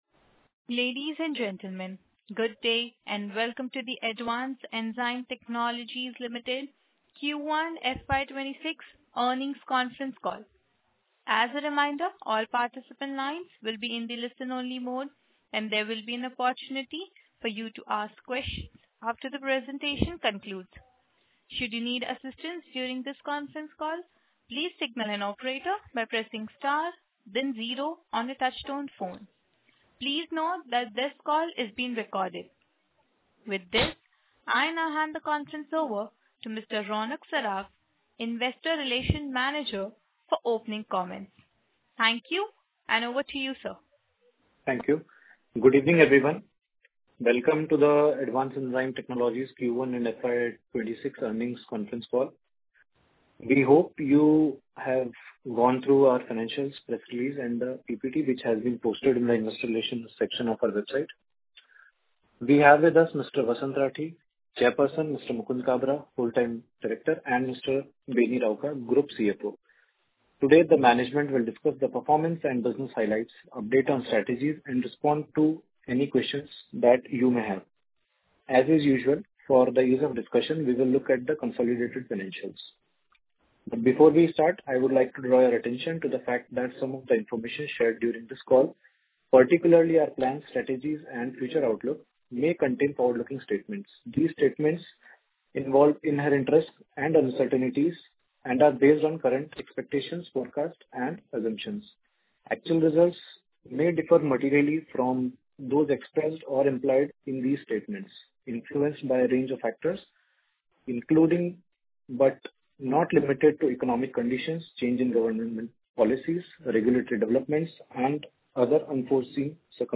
Audio recording of Conference call for Results of Q3 _FY26